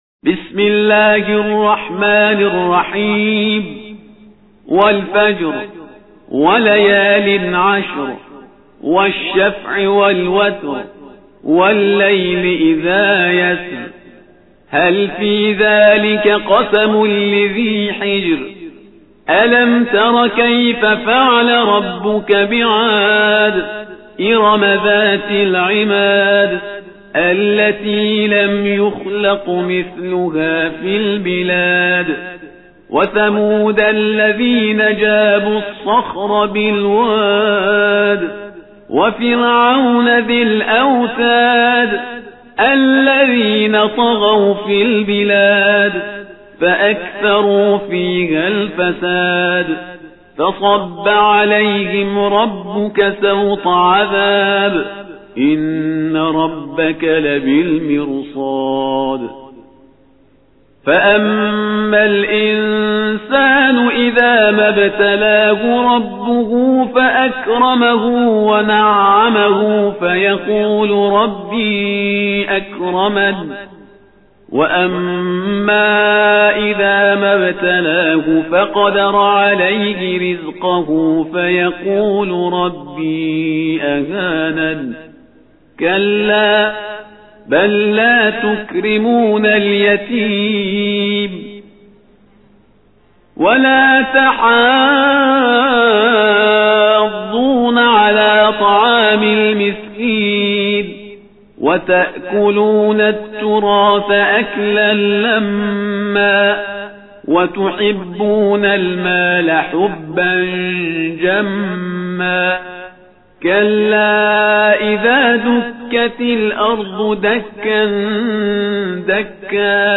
تلاوت ترتیل